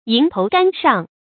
迎头赶上 yíng tóu gǎn shàng 成语解释 赶上最前头的。
成语繁体 迎頭趕上 成语简拼 ytgs 成语注音 ㄧㄥˊ ㄊㄡˊ ㄍㄢˇ ㄕㄤˋ 常用程度 常用成语 感情色彩 中性成语 成语用法 偏正式；作谓语；含褒义 成语结构 偏正式成语 产生年代 当代成语 成语正音 迎，不能读作“nì”。